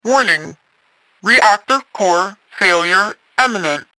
На этой странице собраны звуки работающих реакторов — от глухих гулов до мощных импульсов.
Предупреждение о неминуемом отказе активной зоны реактора